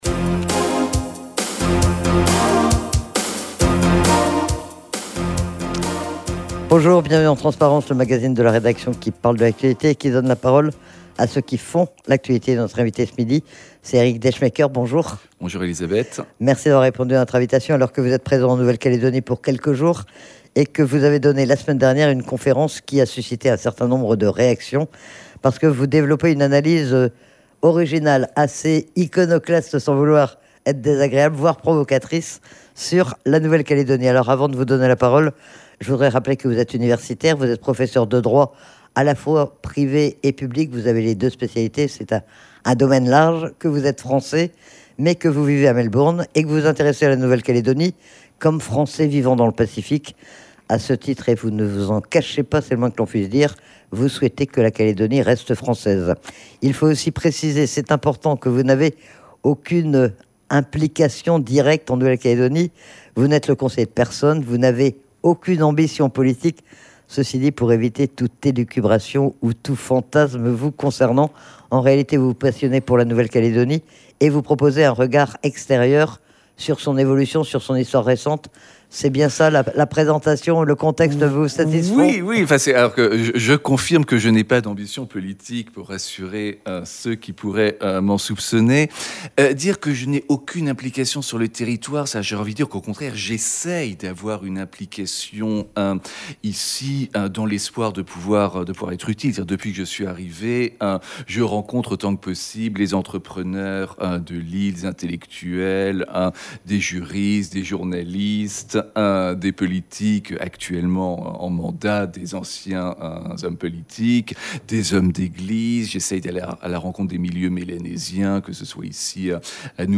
Il était interrogé sur l'analyse originale qu'il développe concernant la situation de la Nouvelle-Calédonie. Il a aussi exposé, à cette occasion, ce qu'il pensait du projet d'accord politique proposé par Manuel Valls.